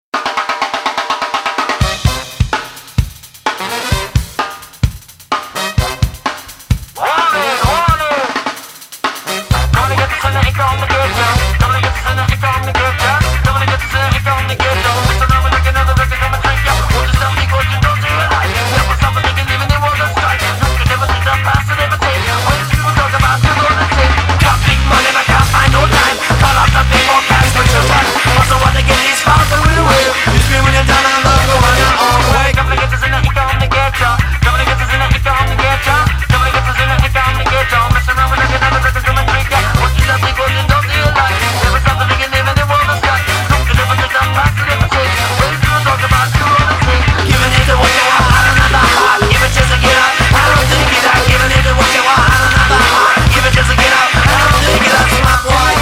a grand mixture of Traditional Ska, Reggae, Swing and Surf
very rich, moving, and catchy as hell.
Trumpet
Tenor Sax, Guitar, B3 Organ
Drums
Bass